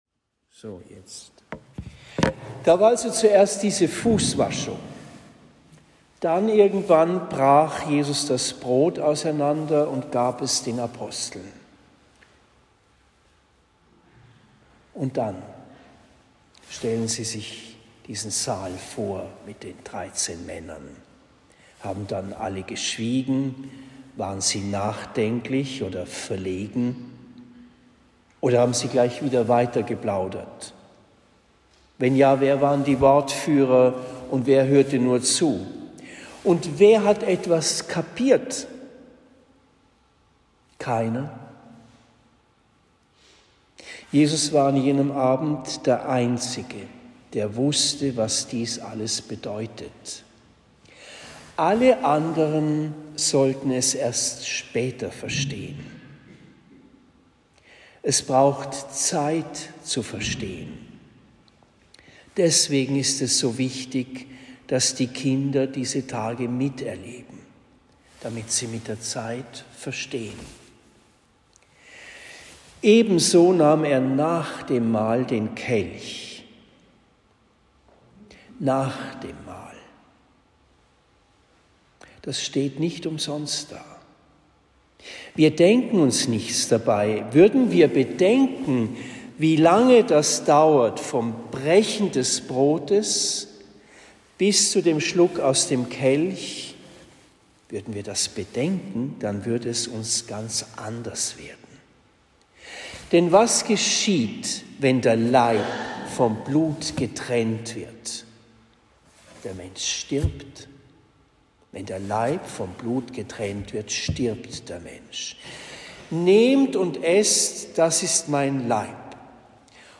Predigt in Trennfeld am Main am 6. April 2023